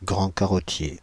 Ääntäminen
Synonyymit machaon grand porte-queue Ääntäminen France (Île-de-France): IPA: /ɡʁɑ̃ ka.ʁɔ.tje/ Haettu sana löytyi näillä lähdekielillä: ranska Käännöksiä ei löytynyt valitulle kohdekielelle.